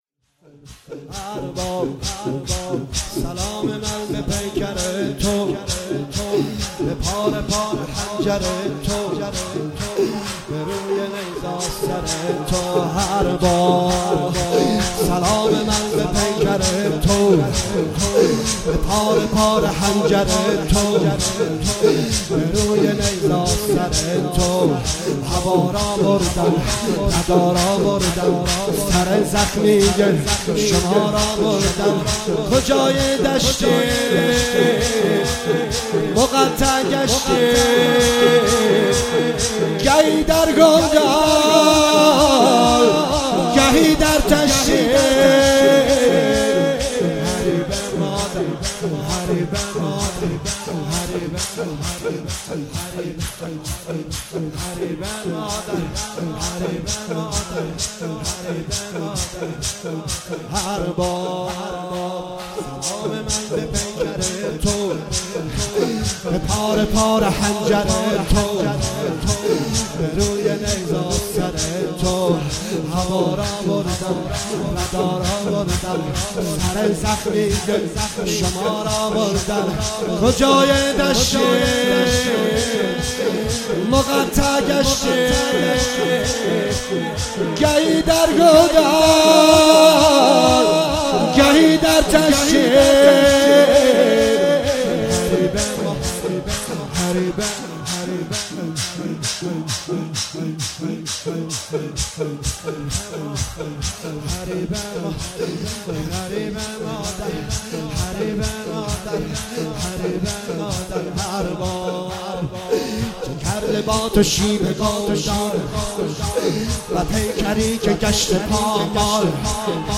9 شهریور 97 - هیئت رزمندگان - شور - ارباب سلام من به پیکر تو